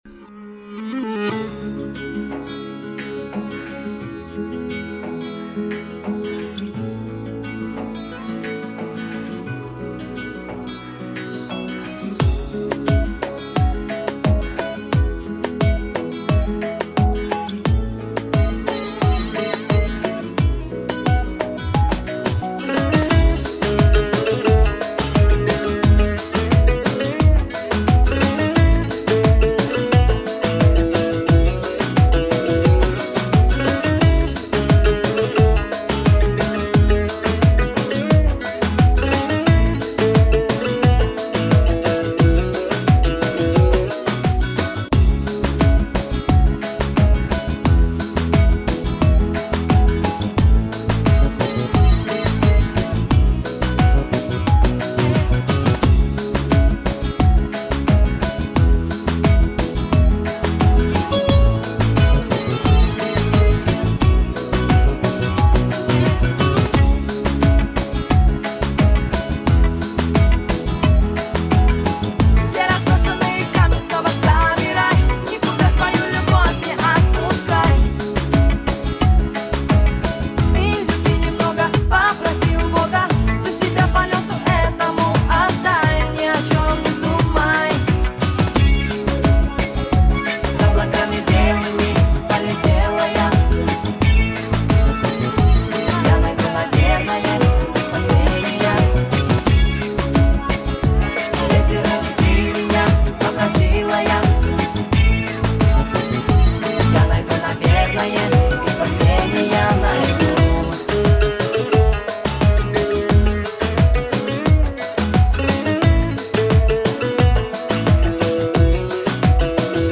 Главная » Файлы » Минусовки » минусы Қазақша